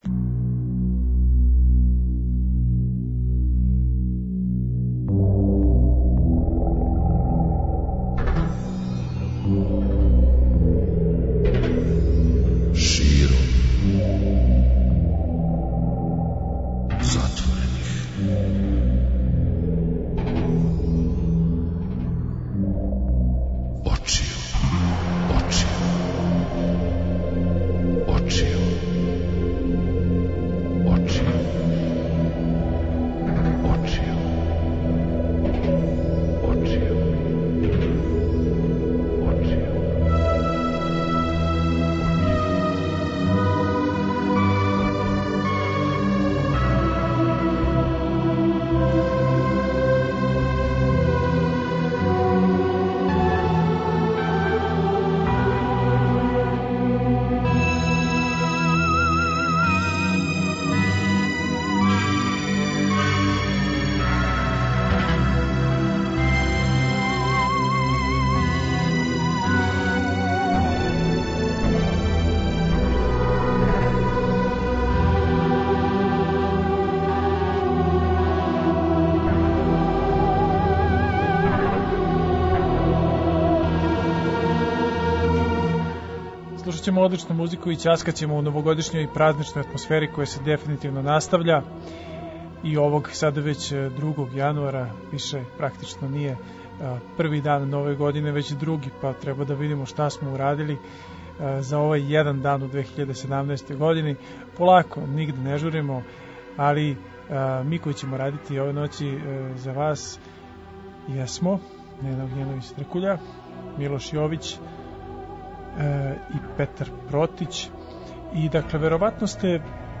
Слушамо одличну музику и ћаскамо у новогодишњој и празничној атмосфери.